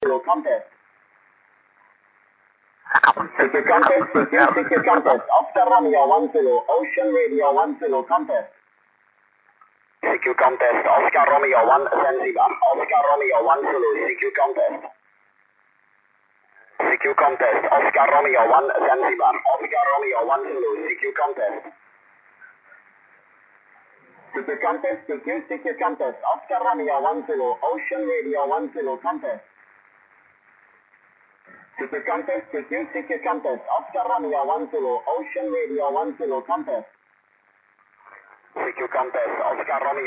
Запись велась: в CW 200Hz, в SSB 2,4kHz.
Продолжаю выкладывать записи работы приемника (tr)uSDX-a. Запись делалась во время проведения IARU HF Championship.
SSB: